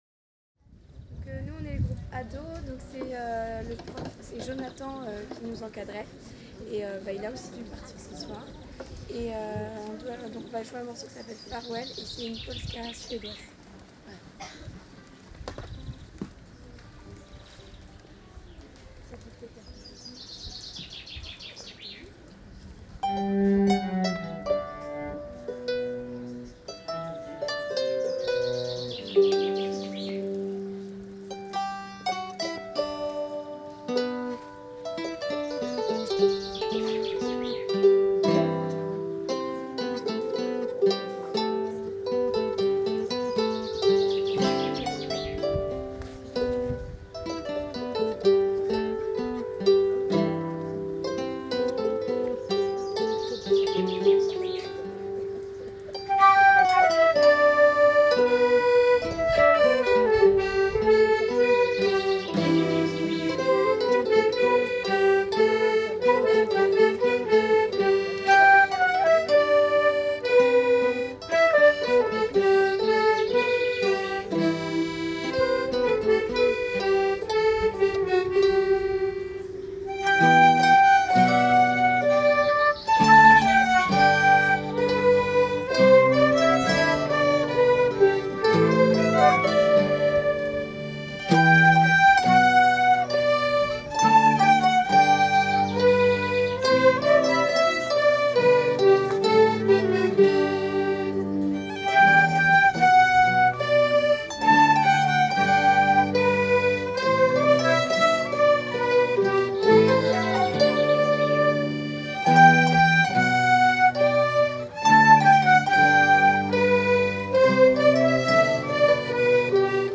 04_ados_far-west_polka.mp3